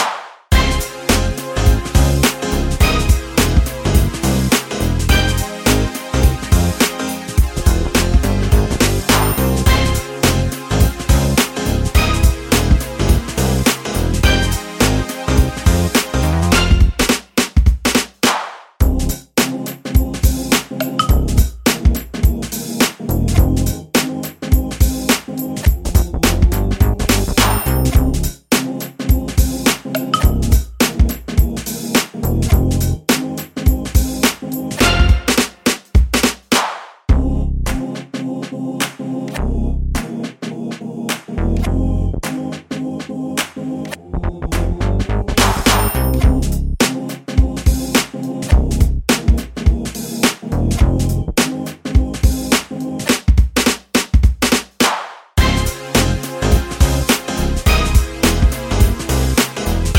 Remix With No Backing Vocals Pop (2010s) 3:38 Buy £1.50